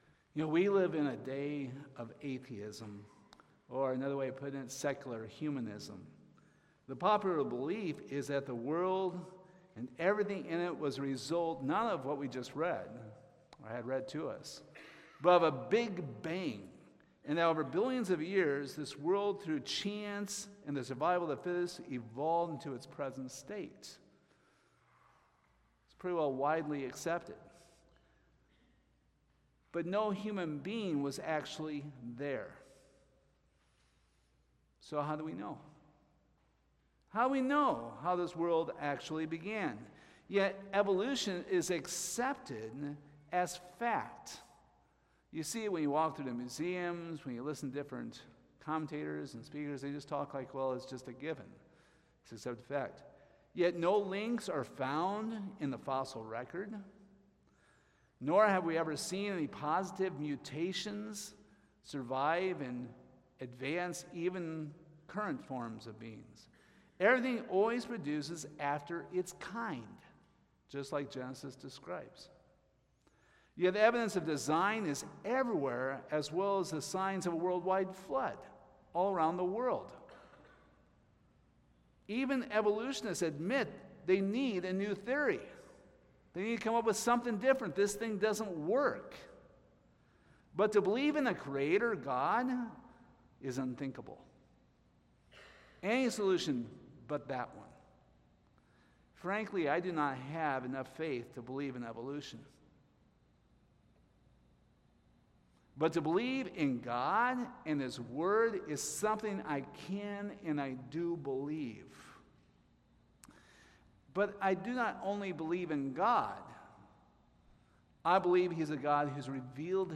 Hebrews 11:3 Service Type: Sunday Morning What part does "faith" play in the theories of evolution?